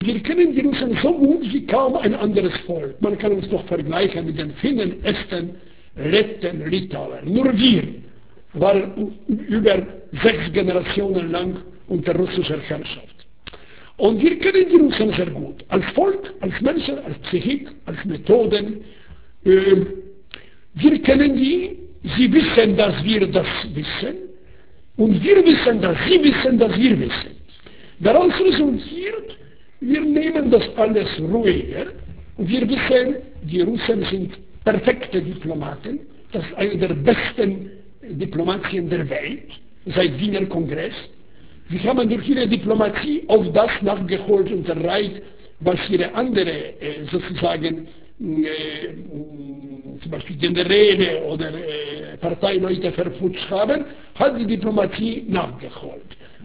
Wladyslav Bartoszewski erzählt am 13. Dezember 1996 im Heinrich-Heine-Institut Düsseldorf: